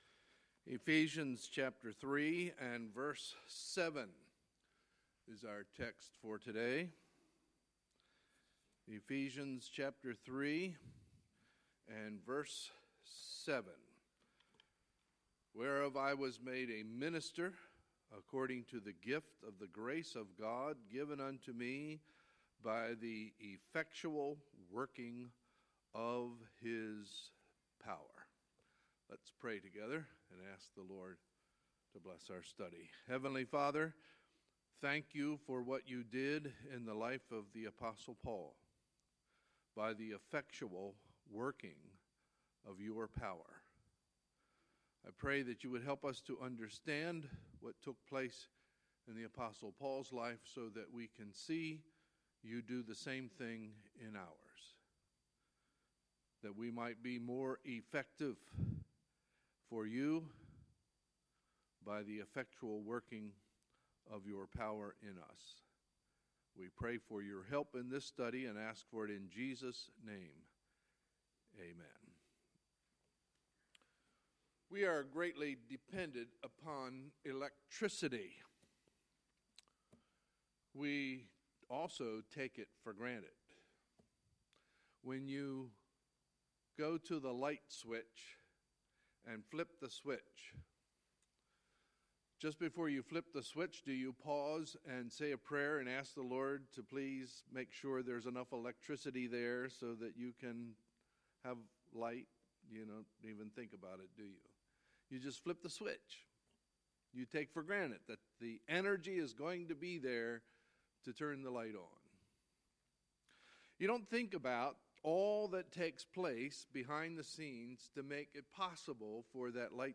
Sunday, January 8, 2017 – Sunday Morning Service